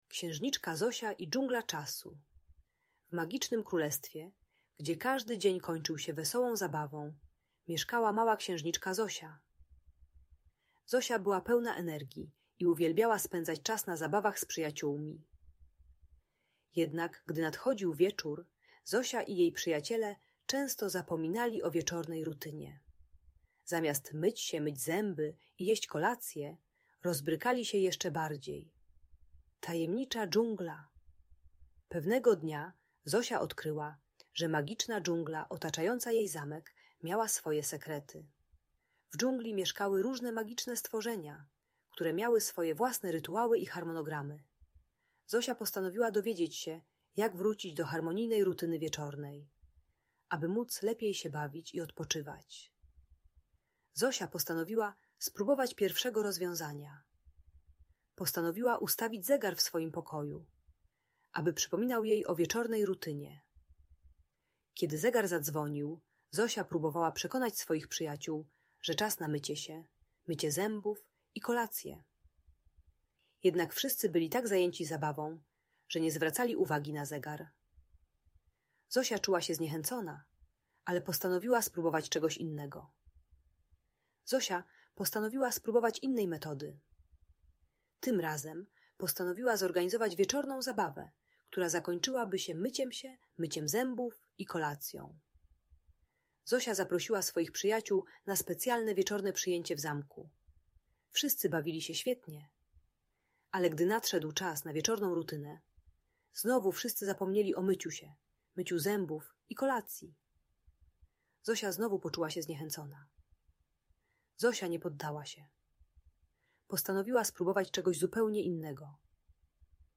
Magiczna historia: Księżniczka Zosia i Dżungla Czasu - Audiobajka